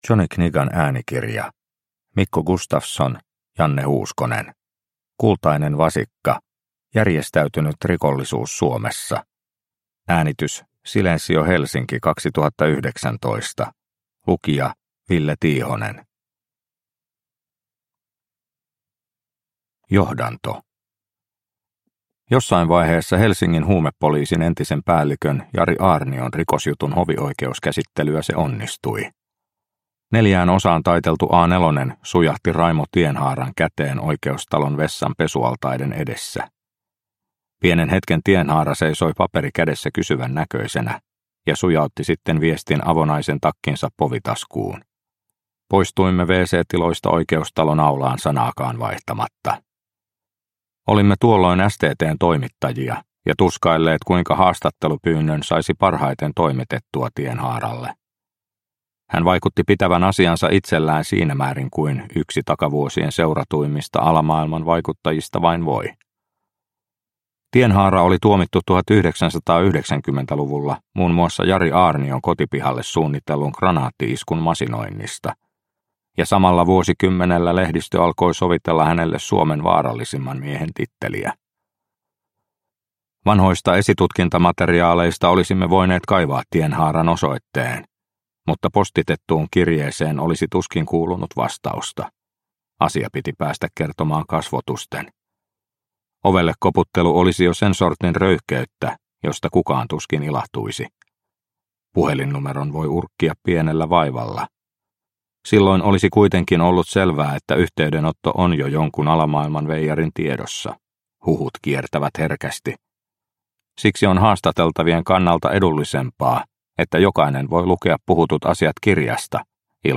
Kultainen vasikka – Ljudbok – Laddas ner